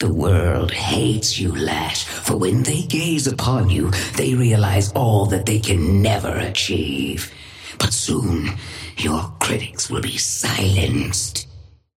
Patron_female_ally_lash_start_02.mp3